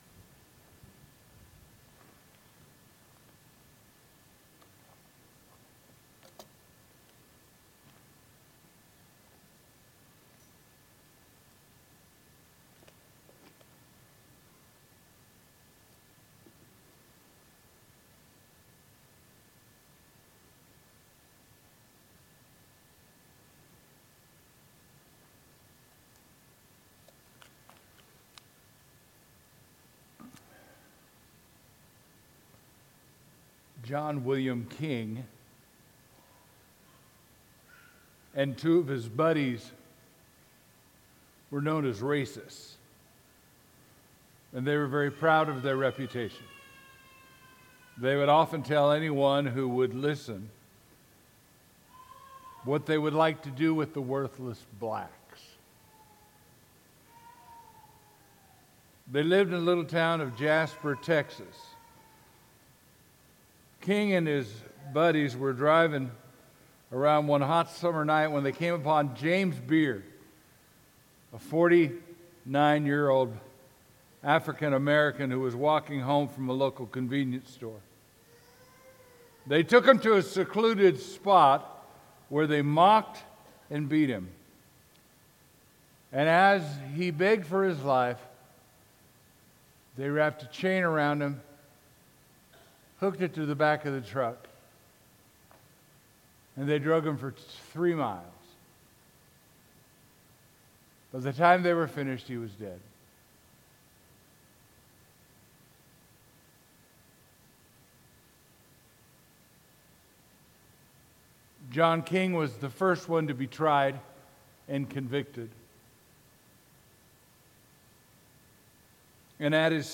Sermon: Words of Jesus: “They Will Hate You”